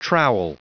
Prononciation du mot trowel en anglais (fichier audio)
Prononciation du mot : trowel